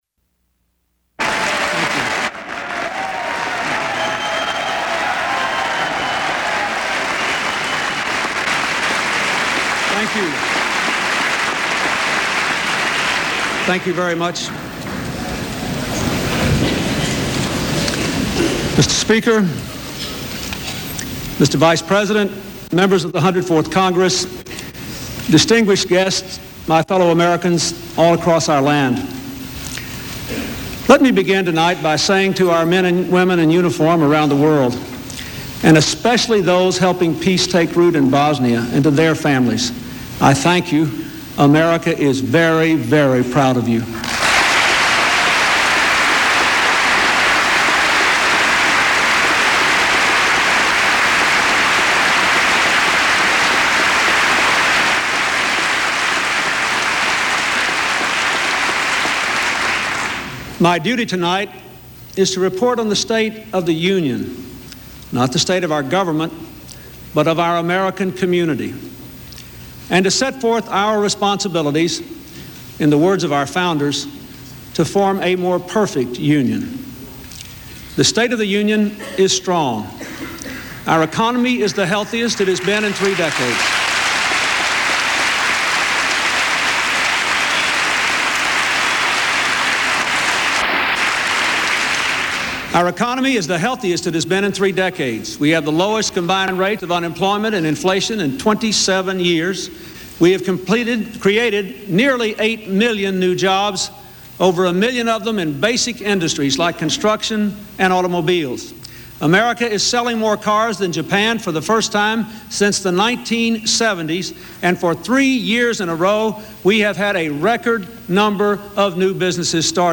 U.S. President Bill Clinton's State of the Union address